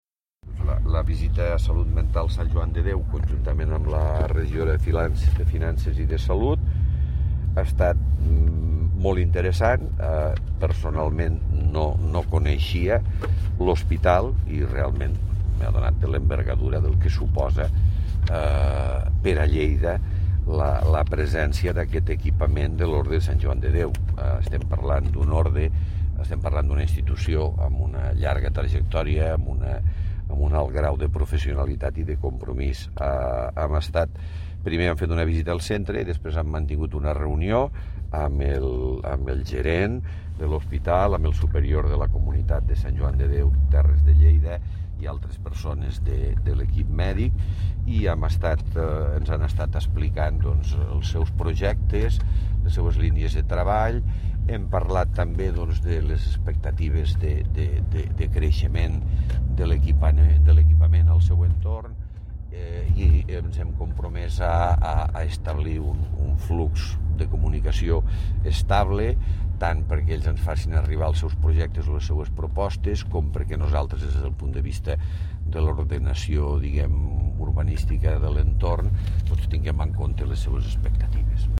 Tall de veu M. Pueyo Estesa a Lleida i arreu del món L’orde Hospitalària de Sant Joan de Déu és una de les majors organitzacions internacionals de cooperació sense ànim de lucre del món.
tall-de-veu-miquel-pueyo